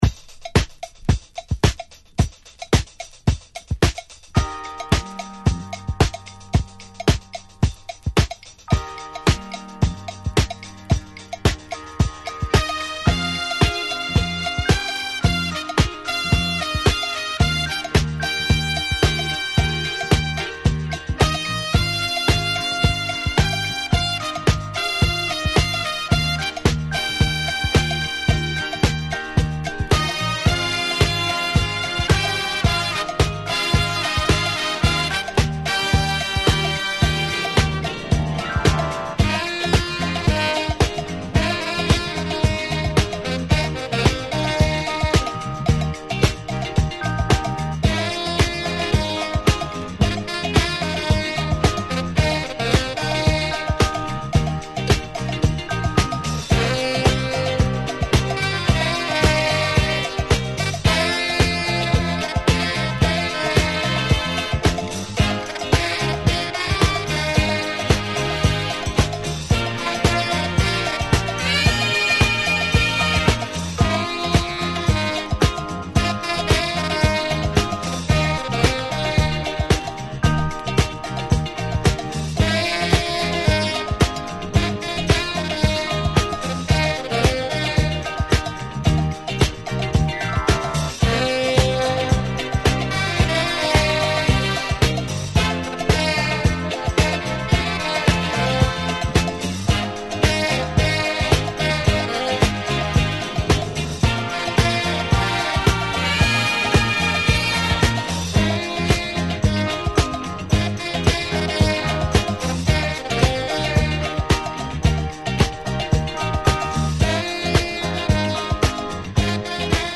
Format: 7 Inch